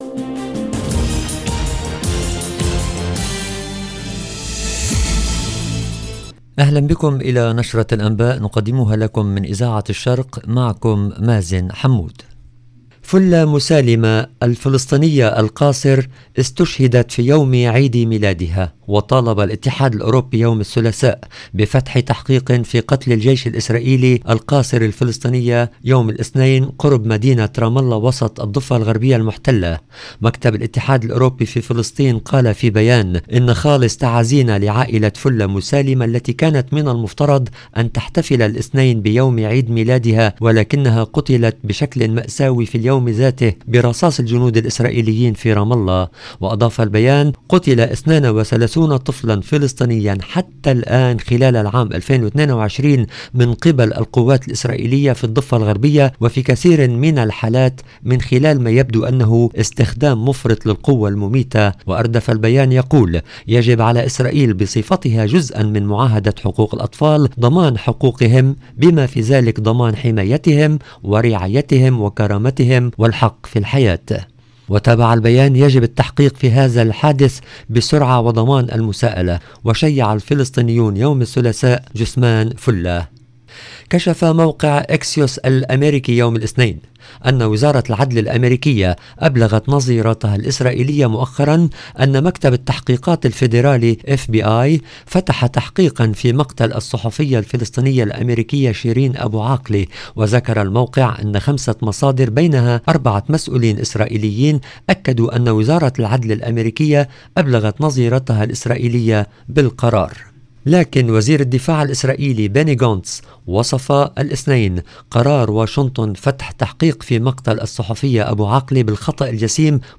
LE JOURNAL EN LANGUE ARABE DU SOIR DU 15/11/22